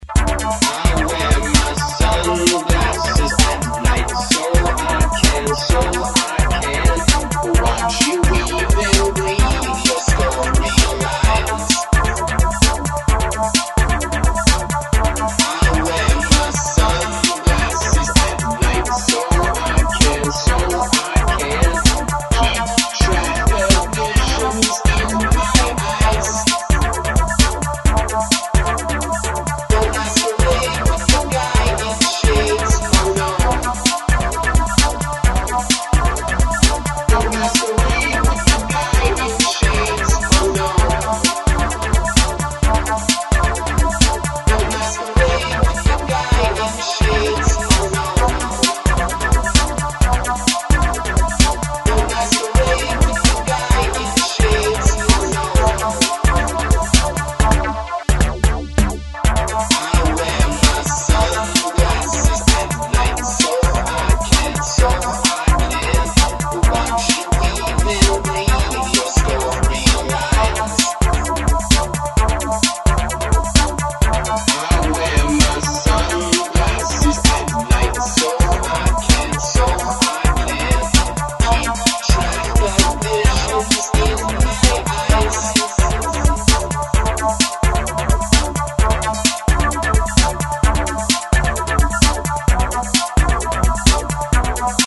Un himno electroclash que define el regreso.